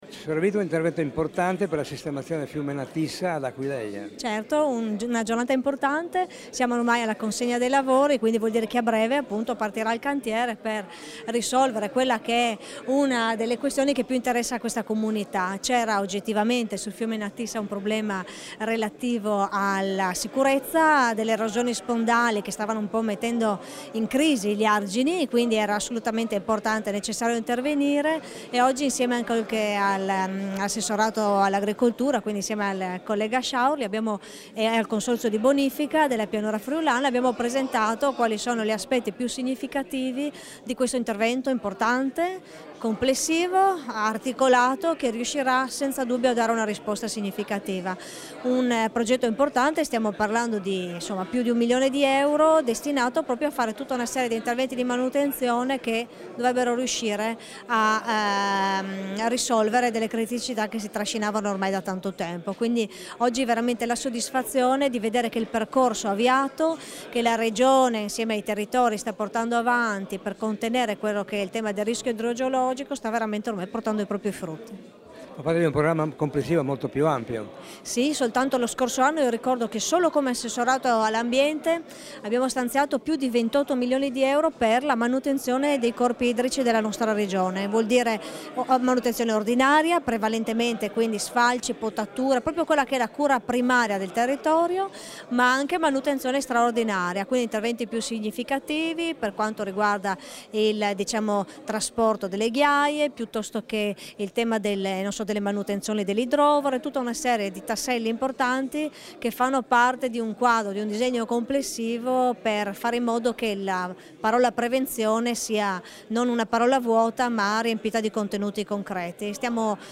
Dichiarazioni di Sara Vito (Formato MP3)
alla consegna dei lavori di sistemazione del fiume Natissa, rilasciate ad Aquileia il 6 marzo 2017